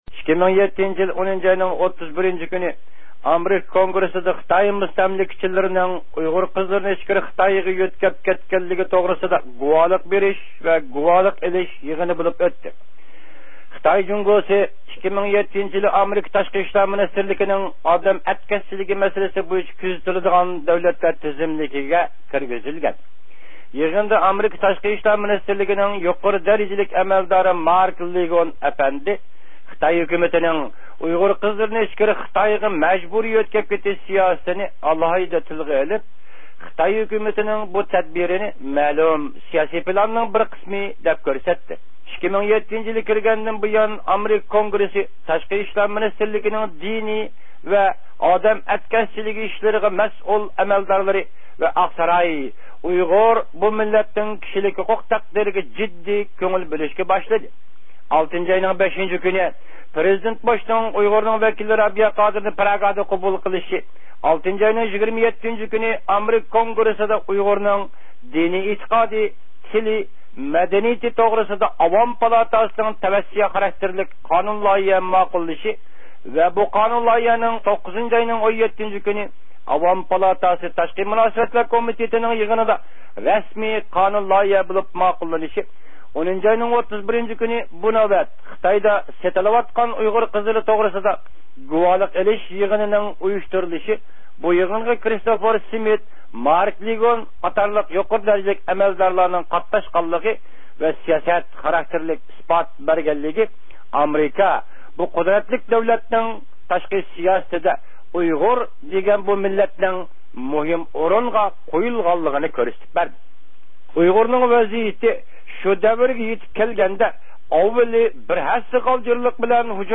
ئوبزور